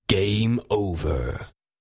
Звуки Game Over
Звук завершения игры мужским голосом